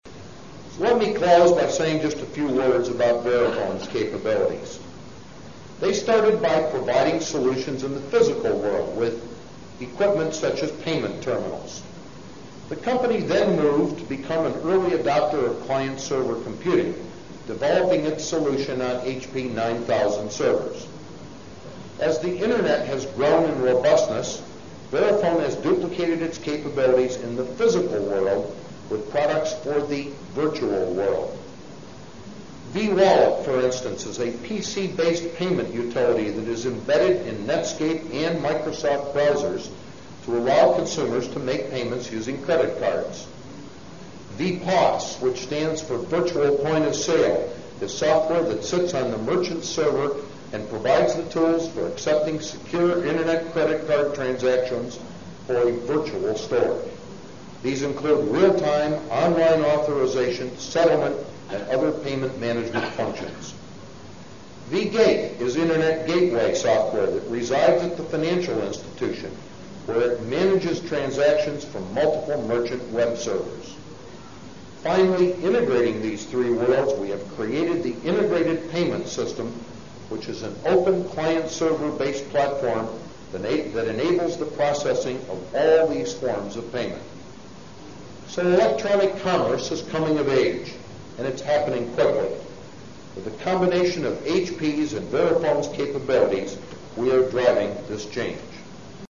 财富精英励志演讲 第167期:携手打造电子化世界(19) 听力文件下载—在线英语听力室